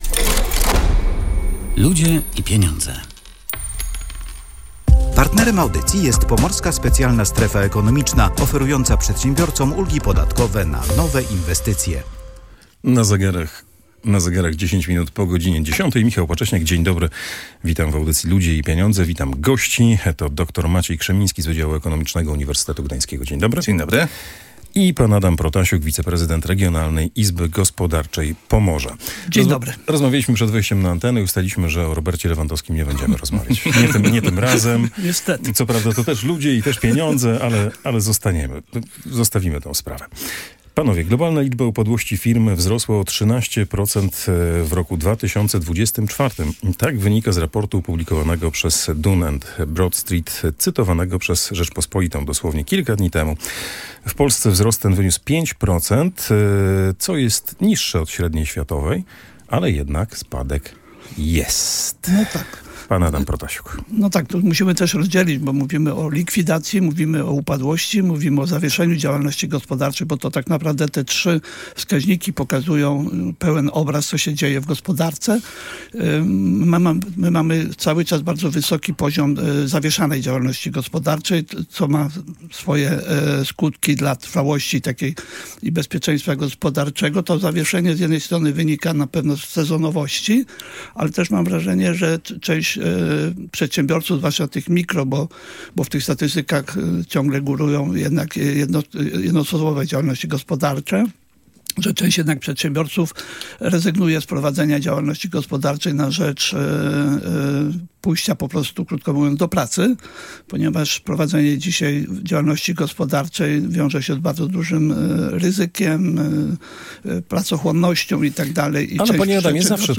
– To dobra decyzja – mówili zgodnie goście audycji „Ludzie i Pieniądze”.